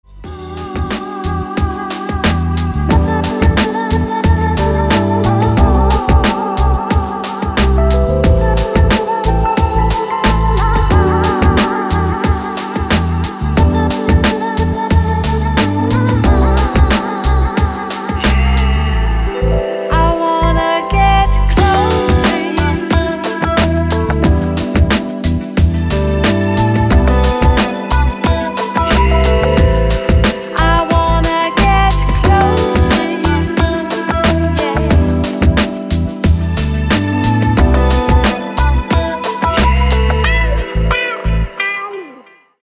• Качество: 320, Stereo
женский вокал
Electronic
электронная музыка
chillout
Downtempo
медленные
Lounge